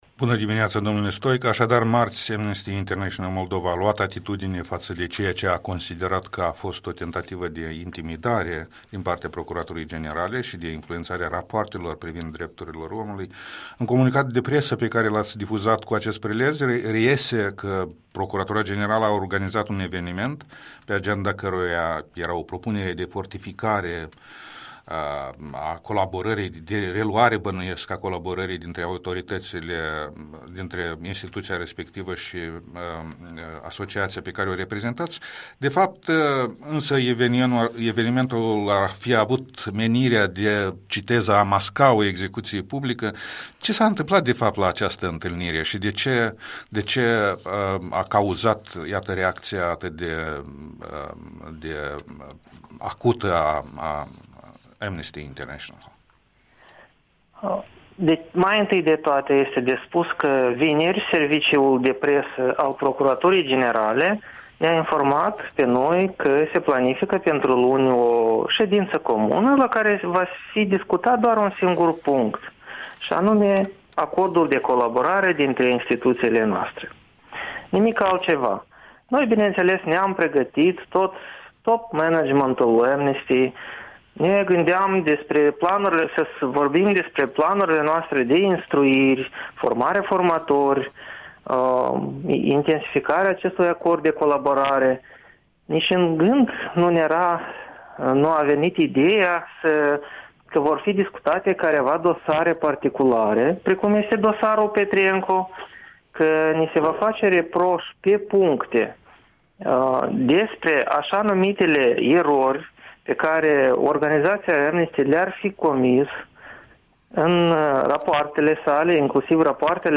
Interviul dimineții cu un coordonator de programe la Amnesty International Moldova.